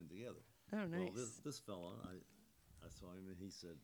Oral history of Shirley Love, 3 of 5
Oak Hill (Fayette County, W. Va.)